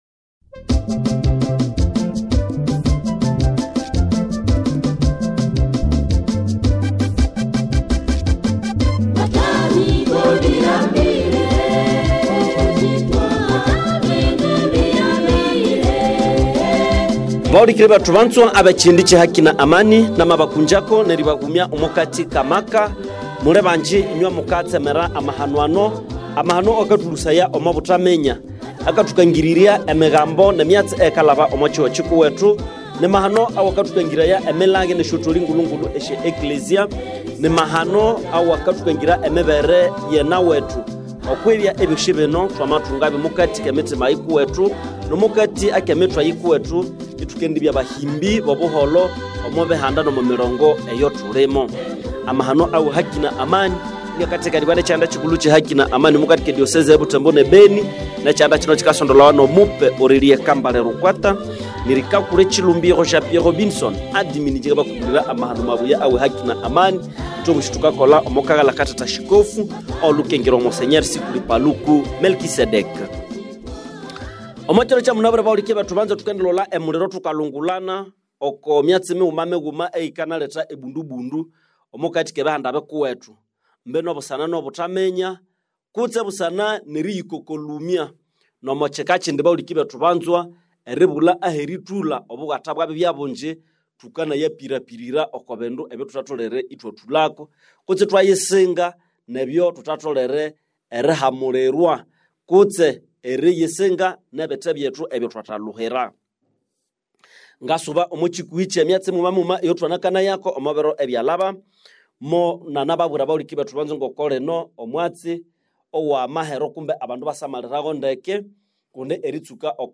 Émission Radio Écoutez l'émission ci-dessous